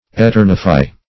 Search Result for " eternify" : The Collaborative International Dictionary of English v.0.48: Eternify \E*ter"ni*fy\, v. t. To make eternal.
eternify.mp3